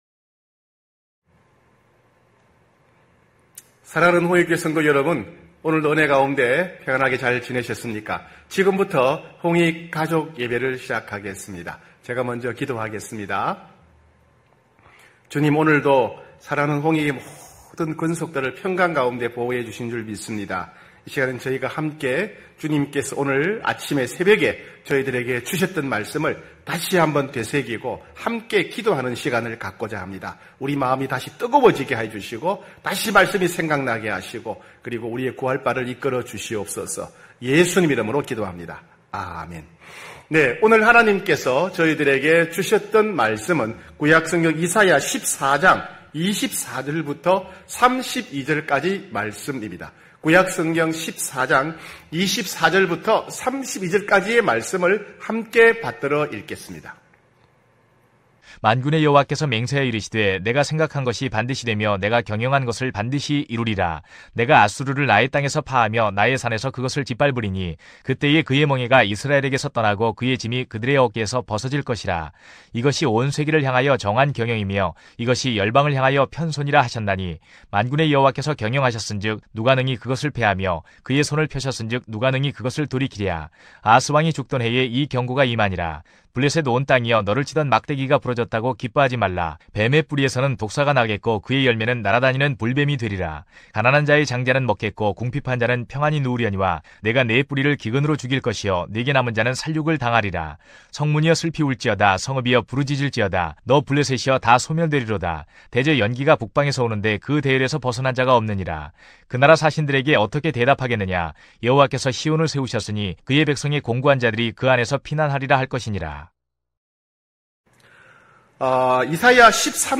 9시홍익가족예배(7월30일).mp3